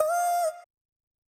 TS - CHANT (4).wav